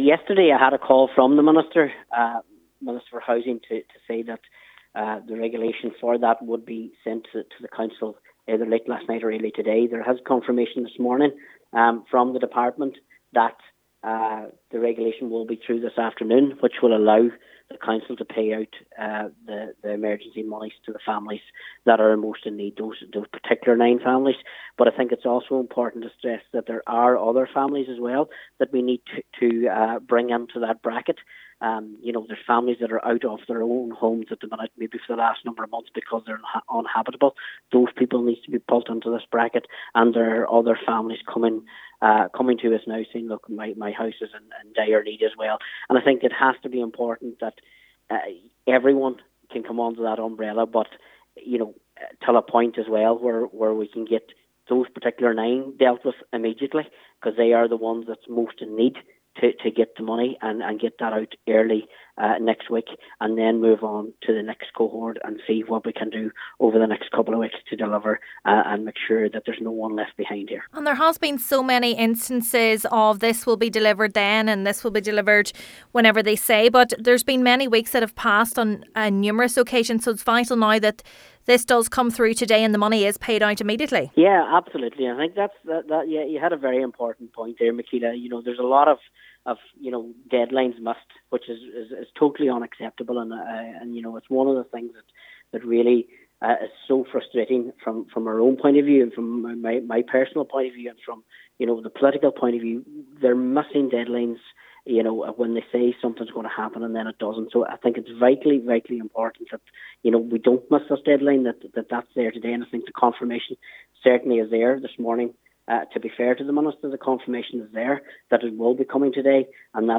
The Chair of Donegal County Council’s Mica Redress Committee, Councillor Martin McDermott says its imperative this announcement is not another missed deadline: